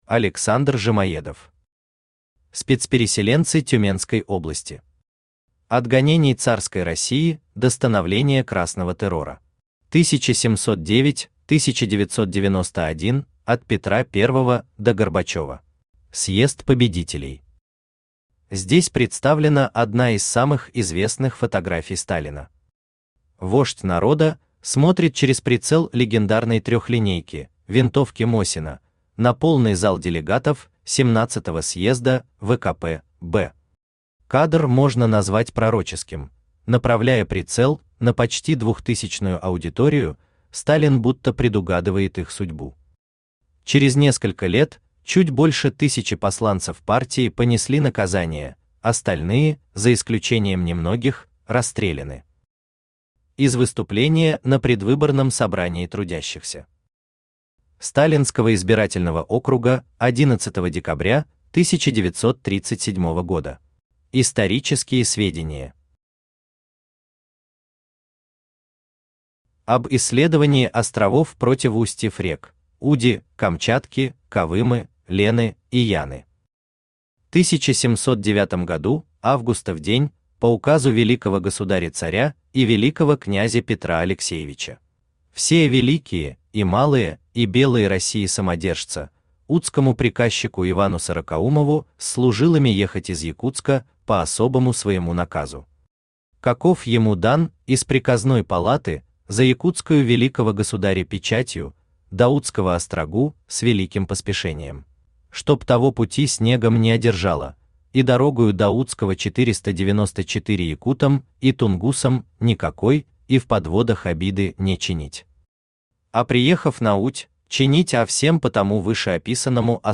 Аудиокнига Спецпереселенцы Тюменской области. От гонений царской России до становления красного террора. 1709 – 1991 (от Петра I до Горбачёва) | Библиотека аудиокниг
От гонений царской России до становления красного террора. 1709 – 1991 (от Петра I до Горбачёва) Автор Александр Викторович Жимоедов Читает аудиокнигу Авточтец ЛитРес.